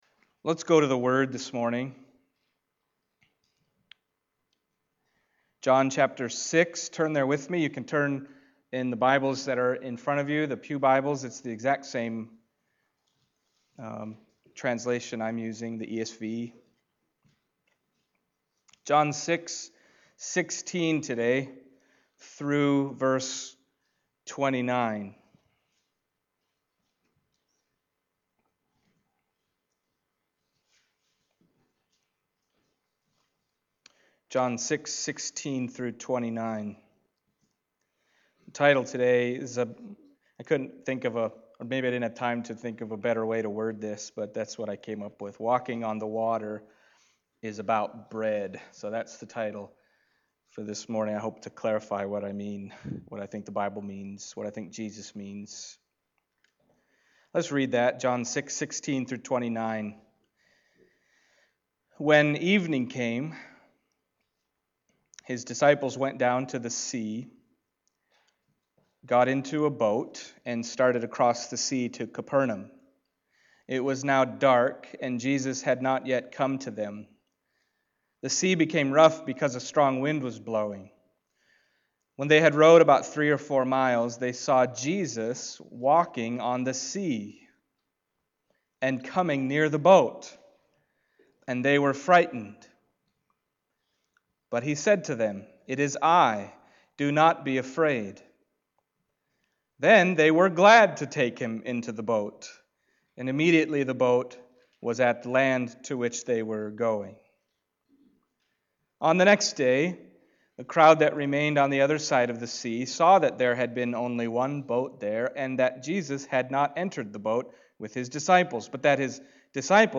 John Passage: John 6:16-29 Service Type: Sunday Morning John 6:16-29 « What Kind of Messiah Is Jesus?